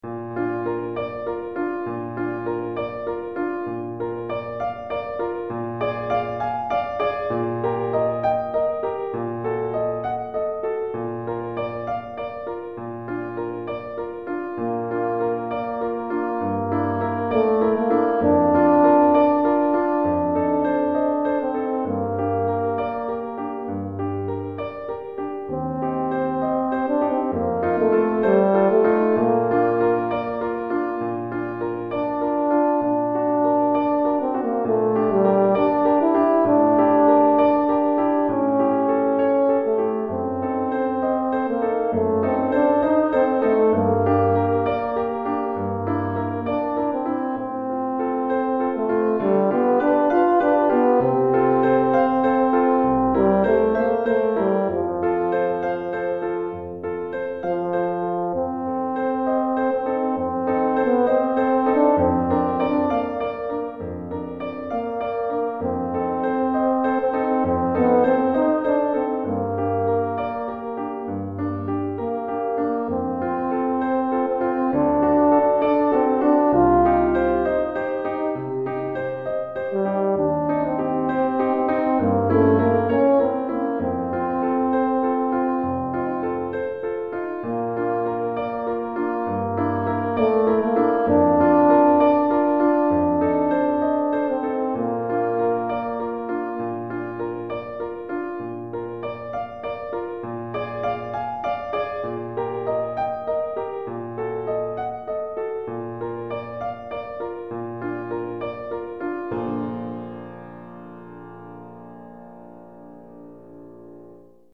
Cor et Piano